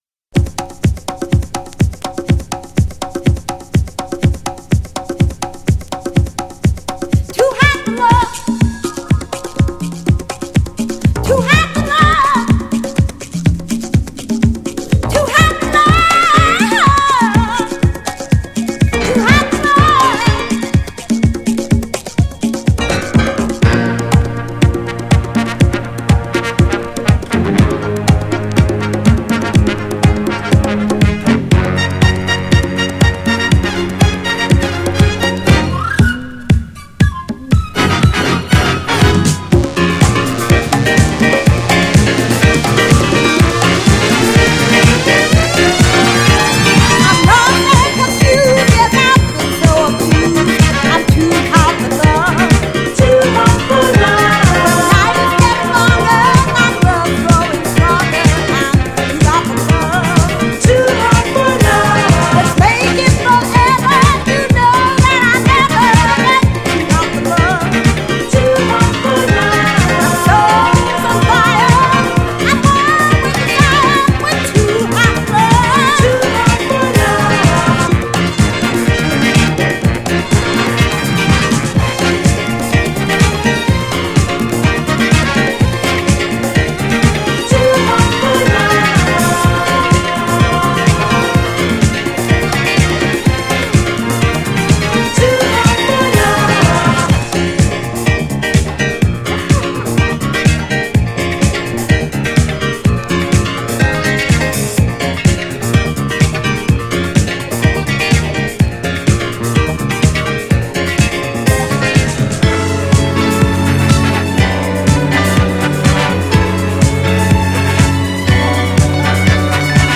ボーカルバージョンでリメイクしたB面も人気!!
GENRE Dance Classic
BPM 116〜120BPM
アップリフティング # オーケストラ # ストリングス # ドラムブレイク # 妖艶